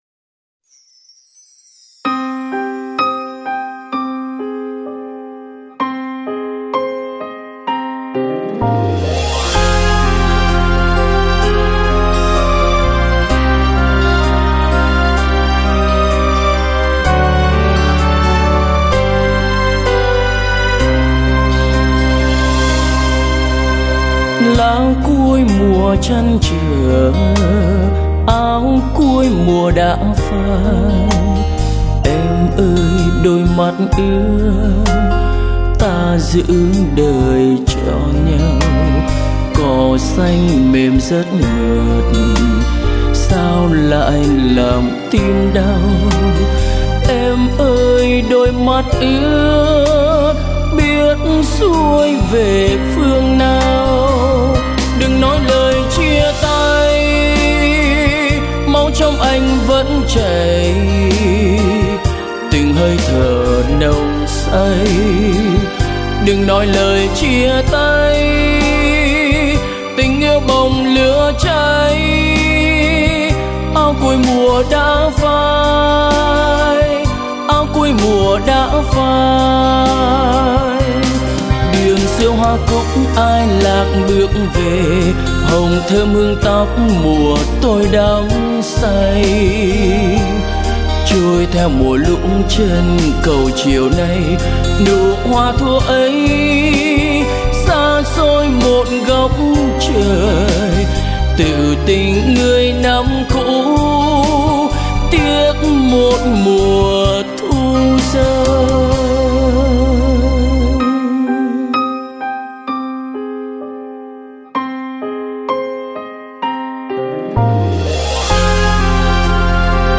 Hòa âm: Studio 7 nốt nhạc https